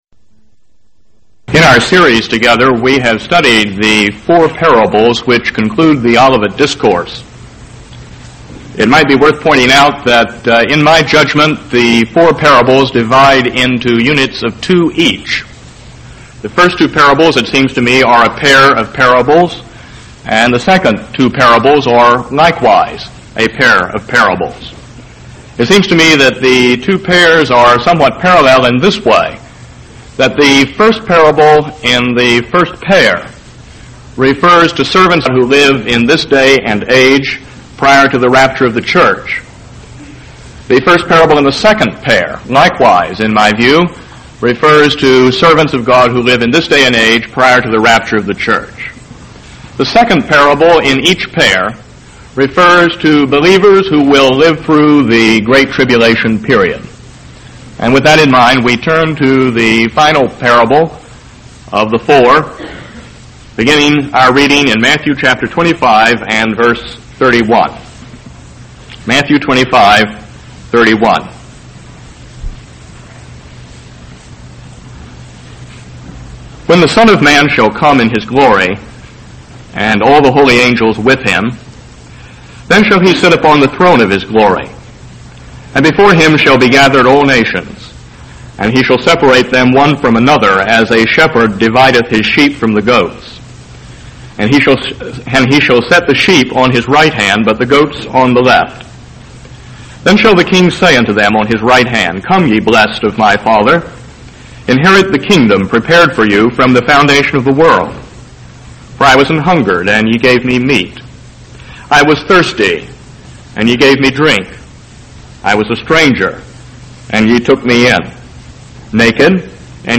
A 1975 message on Matthew 25:31–46, exploring the judgment of the sheep and the goats and the difference between entering the kingdom and inheriting it.